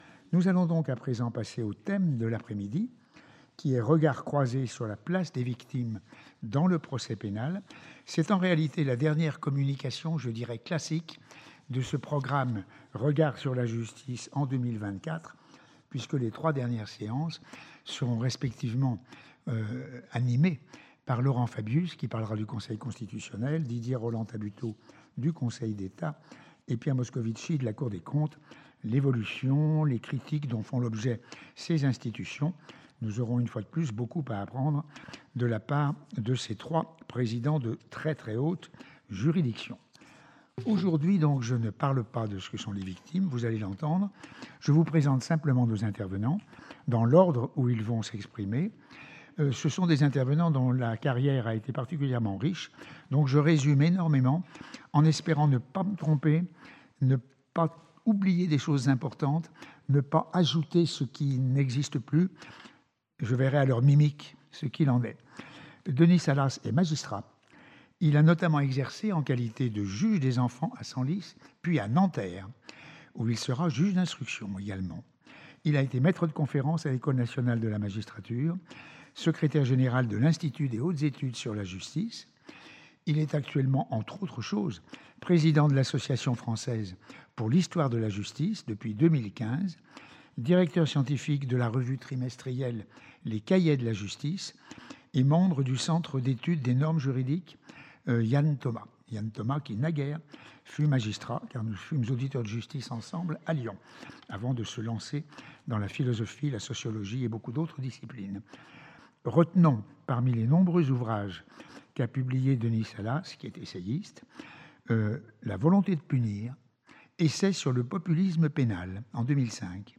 À l’issue de leur communication à deux voix